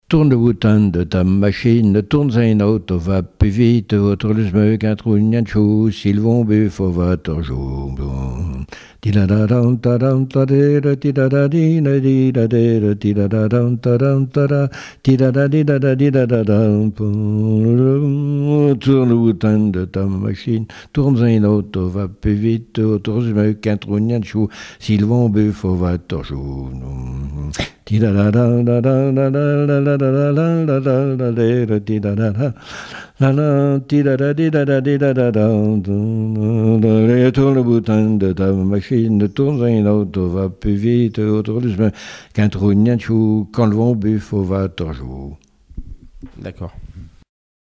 danse : branle : avant-deux
Témoignages et chansons
Pièce musicale inédite